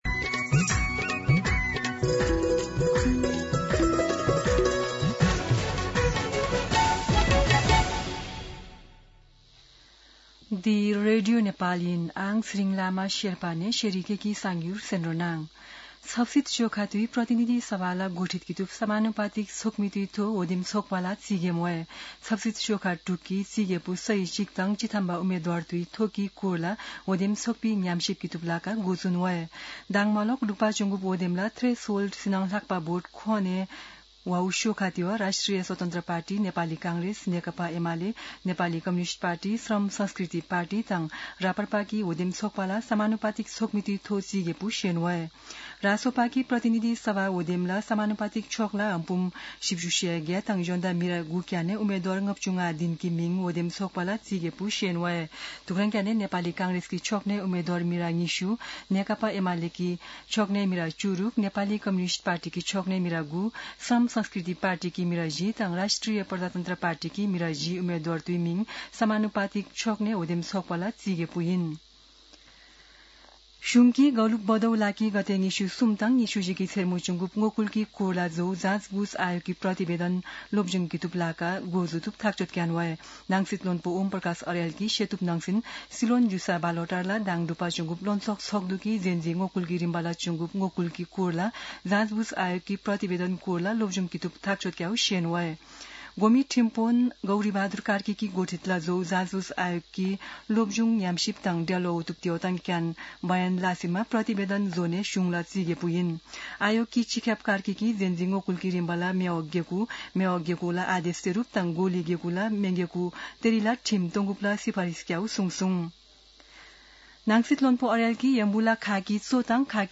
शेर्पा भाषाको समाचार : २ चैत , २०८२
Sherpa-News-02.mp3